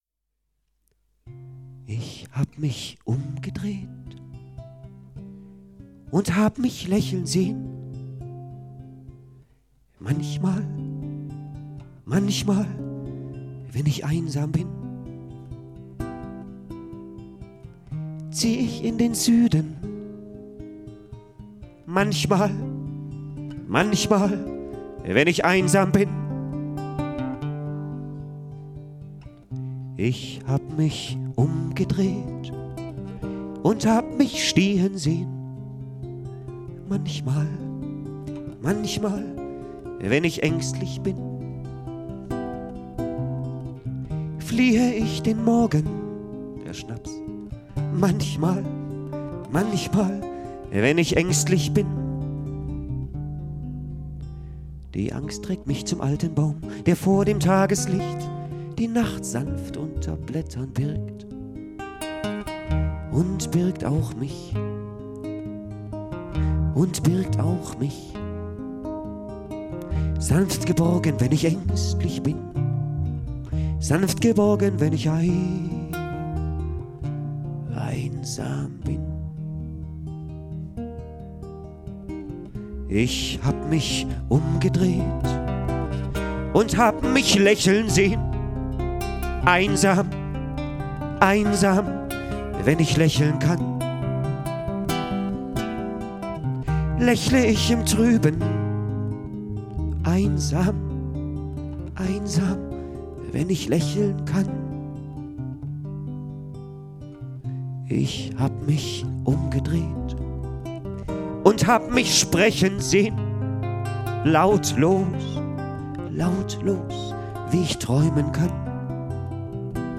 bass